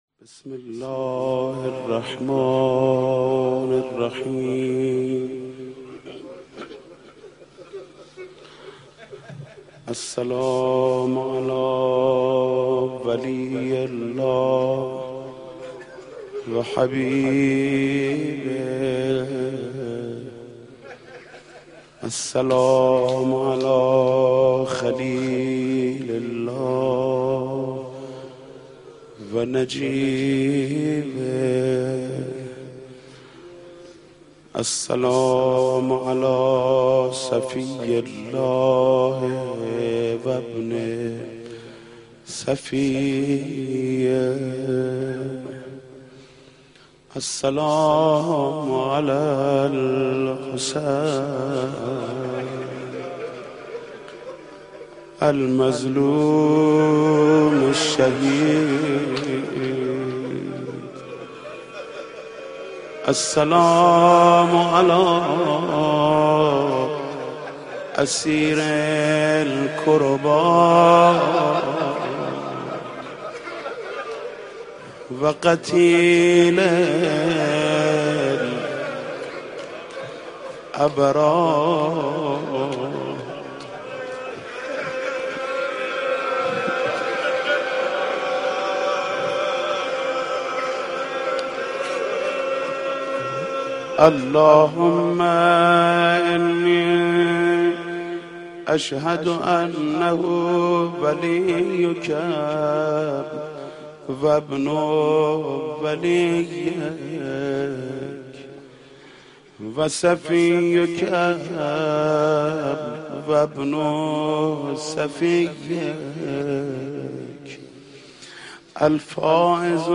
زیارت اربعین با نوای حاج محمود کریمی در ادامه قابل بهره برداری است.